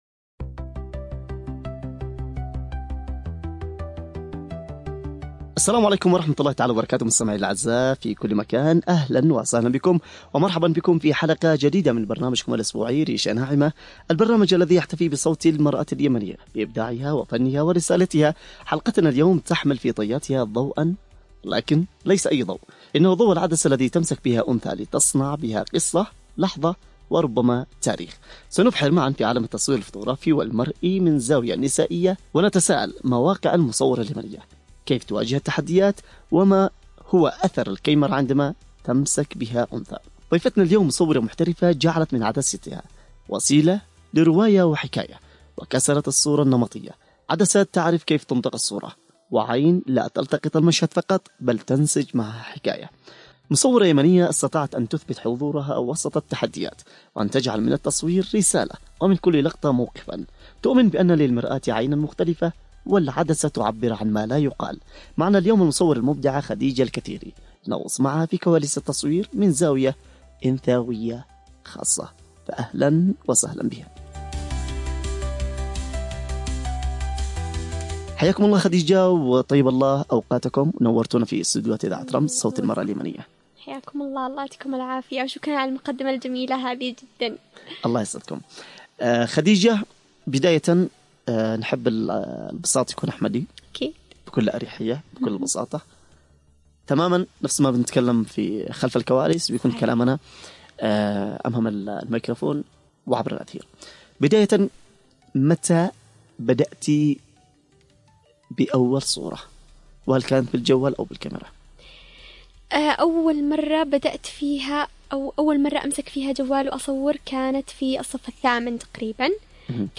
في حوار بصري ملهم عن تجربة المرأة اليمنية في التصوير الفني والمهني، وكيف تحوّلت الكاميرا إلى وسيلة للتعبير، واللقطة إلى رسالة.